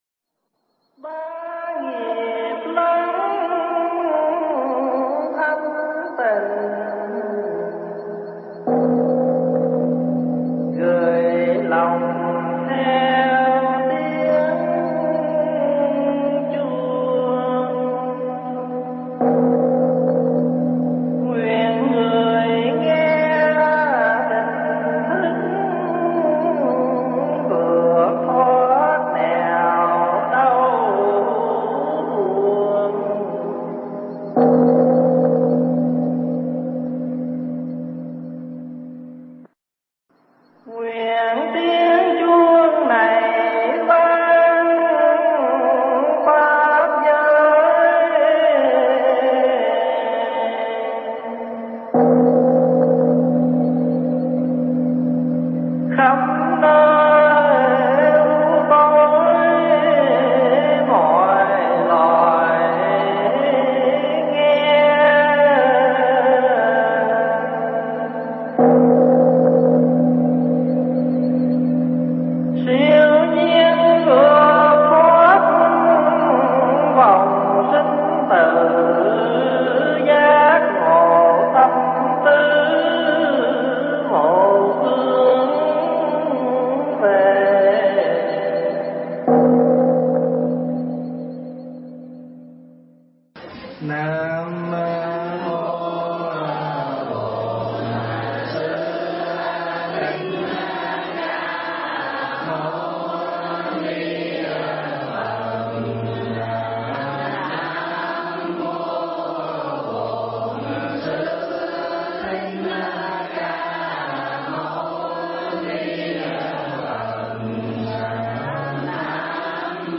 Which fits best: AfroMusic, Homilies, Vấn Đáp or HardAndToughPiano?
Vấn Đáp